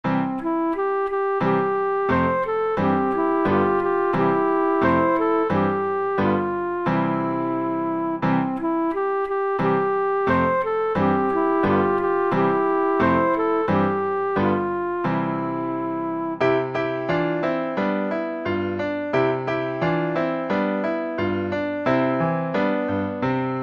Vánoční koleda pro 2 zobcové flétny a klavír